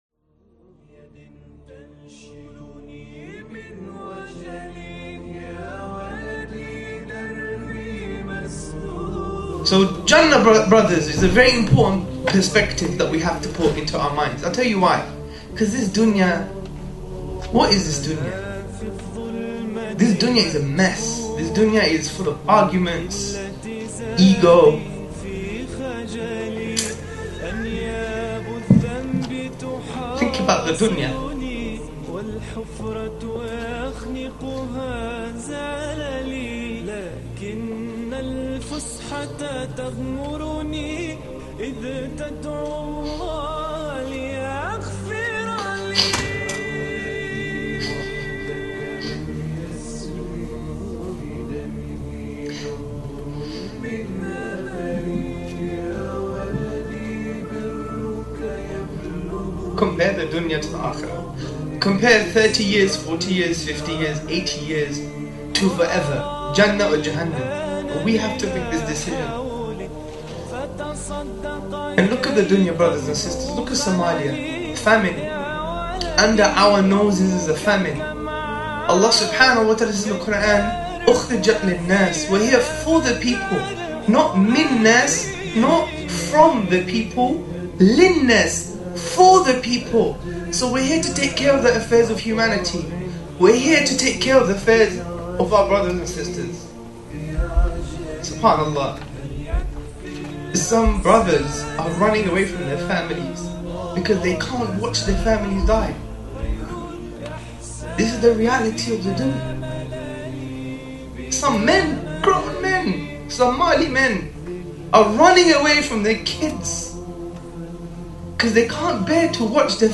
An emotional message